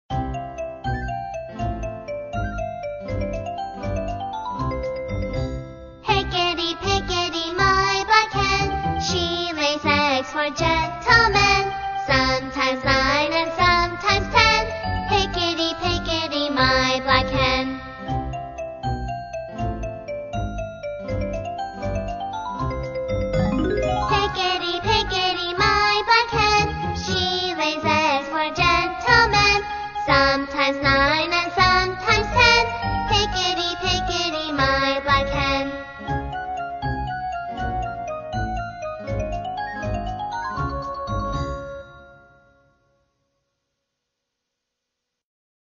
音乐节奏活泼动人的英文儿歌